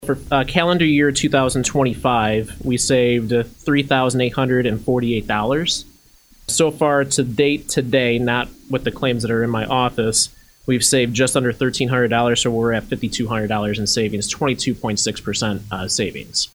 During this morning’s meeting of the Kankakee County Board’s Finance Committee, the committee got an update from Auditor Colton Ekhoff on the success of the bulk-buying program he’s instituted through Staples